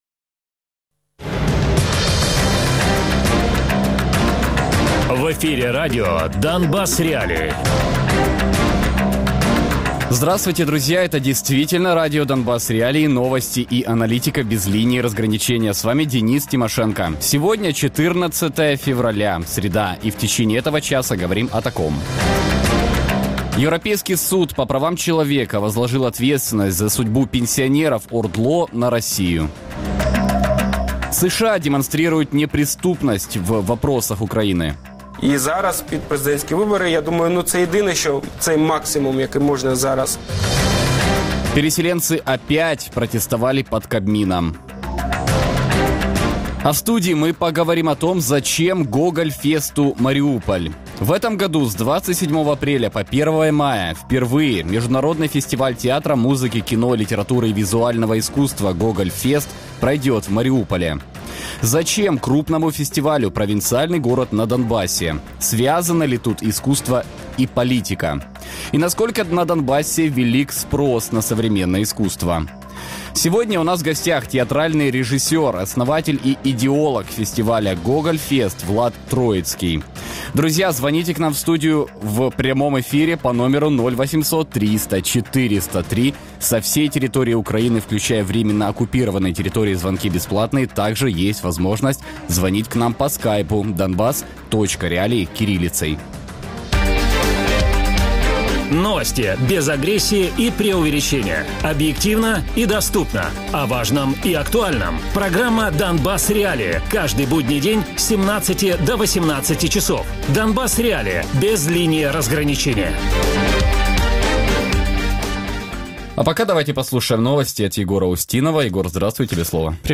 Гість: Влад Троїцький - засновник і ідеолог фестивалю «ГогольFest», театральний режисер. Радіопрограма «Донбас.Реалії» - у будні з 17:00 до 18:00. Без агресії і перебільшення. 60 хвилин про найважливіше для Донецької і Луганської областей.